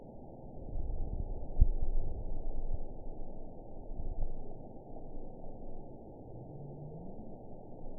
event 921941 date 12/23/24 time 04:06:46 GMT (4 months, 2 weeks ago) score 8.97 location TSS-AB03 detected by nrw target species NRW annotations +NRW Spectrogram: Frequency (kHz) vs. Time (s) audio not available .wav